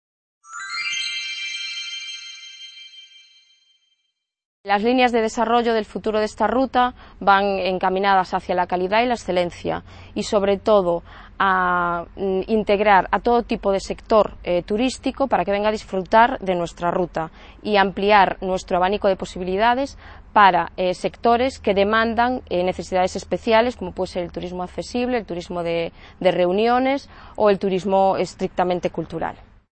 C.A. Ponferrada - II Congreso Territorial del Noroeste Ibérico